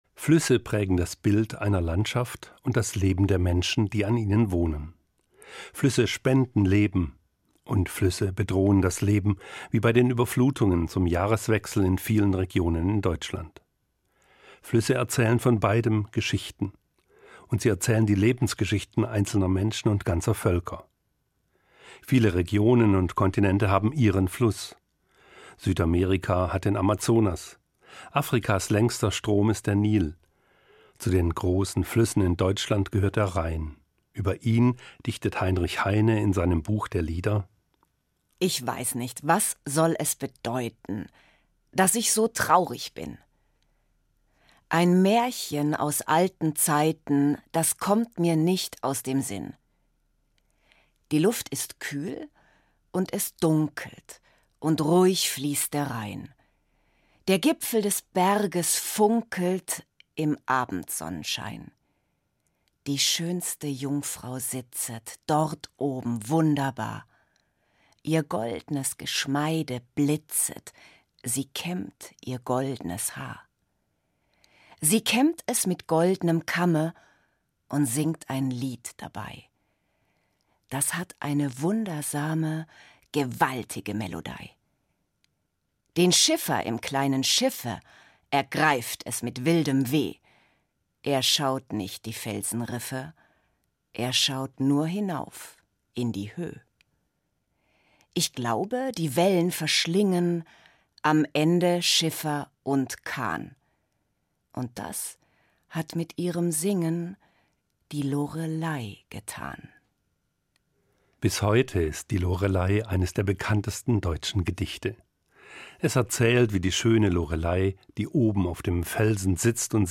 Sprecherin der Zitate